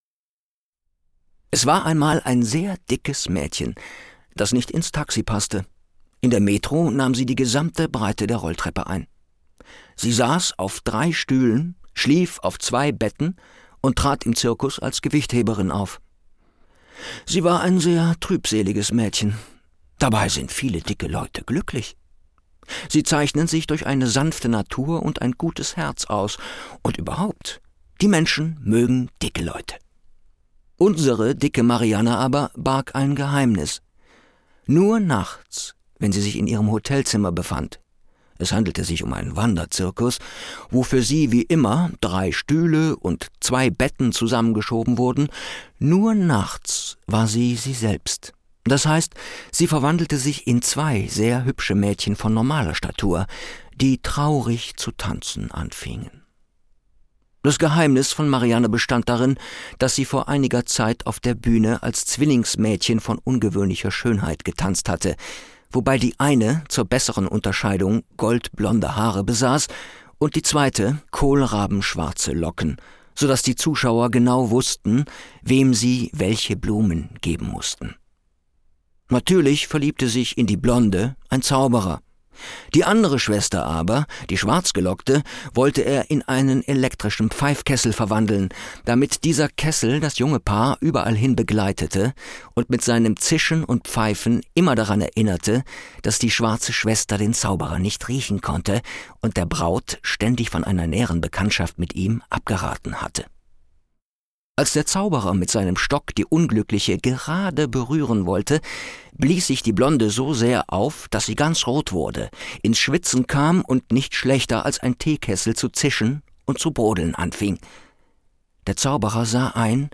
Die Hörproben im Demobereich dienen der Präsentation meiner Bandbreite als Sprecher für Funk TV und Multimedia.
Sprechprobe: Sonstiges (Muttersprache):